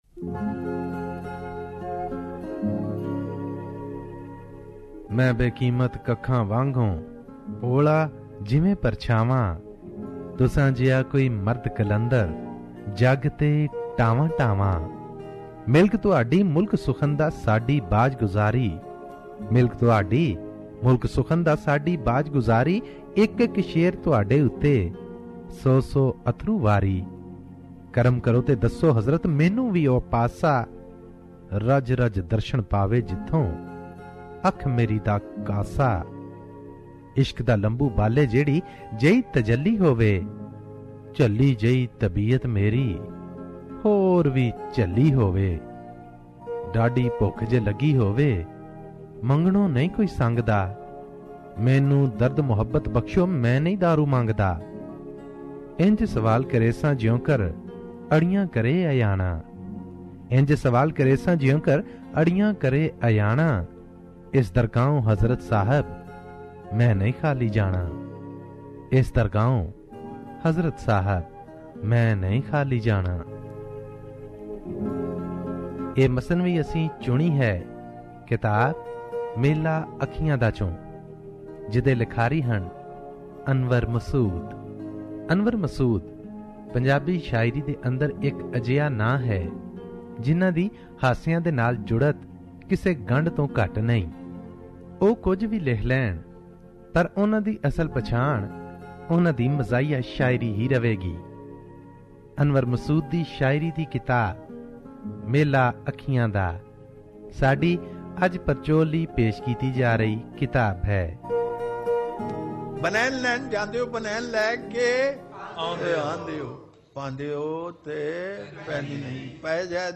Book review